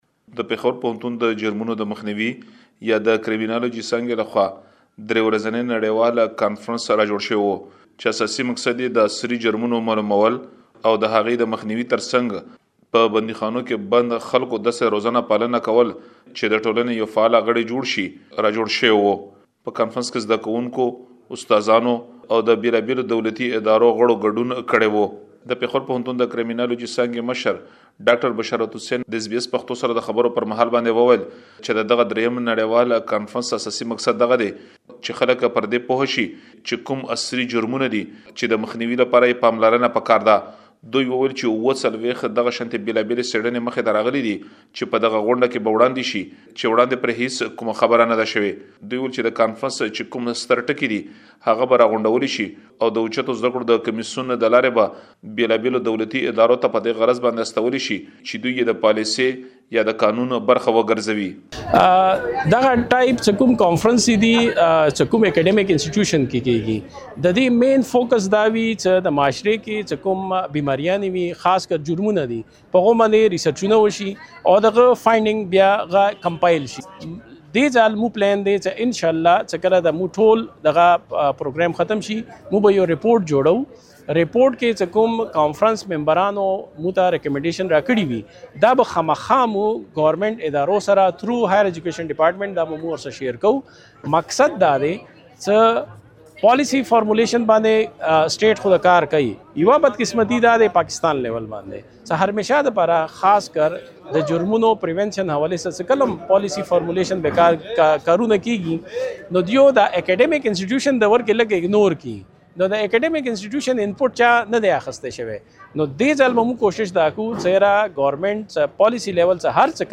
په دغه کنفرانس کې د پوهنتون د استادانو او زده کړیالانو ترڅنګ، یو شمېر متخصصینو او دولتي چارواکو ګډون کړی و. مهرباني وکړئ په دې اړه لا ډېر معلومات په رپوټ کې واورئ.